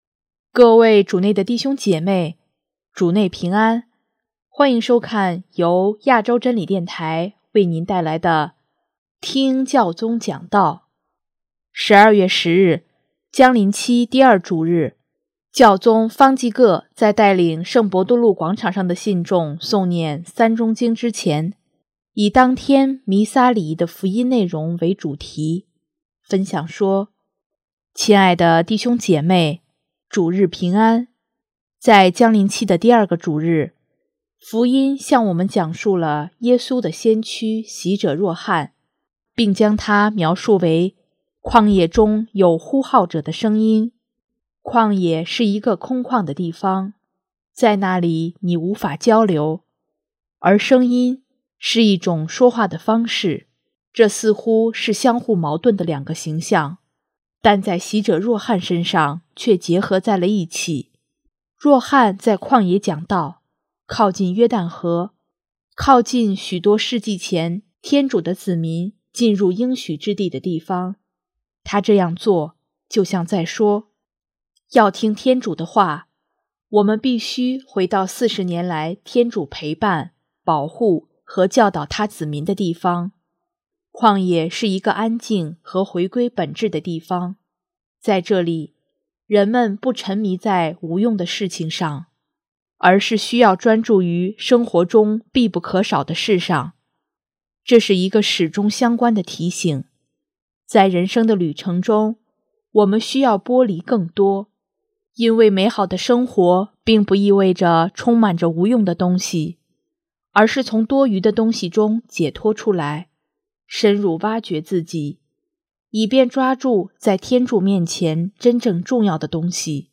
12月10日，将临期第二主日，教宗方济各在带领圣伯多禄广场上的信众诵念《三钟经》之前，以当天弥撒礼仪的福音内容为主题，分享说：